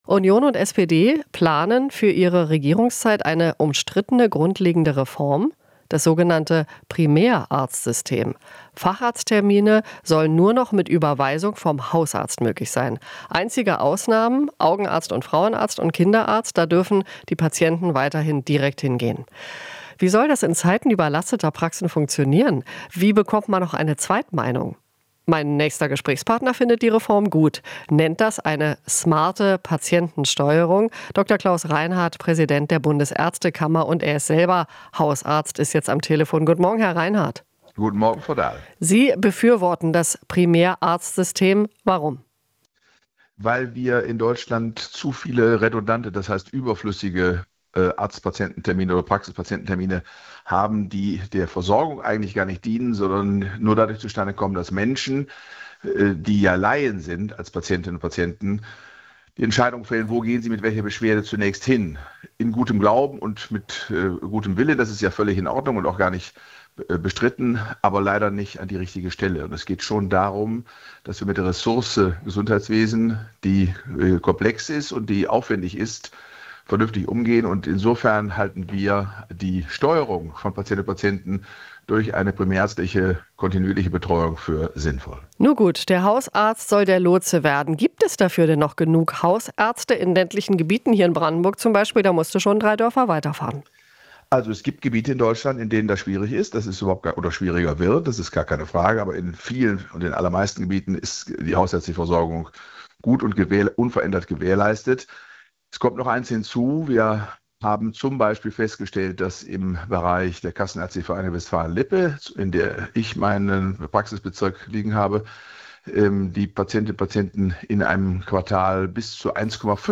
Interview - Bundesärztekammer: Bei unklaren Beschwerden zum Hausarzt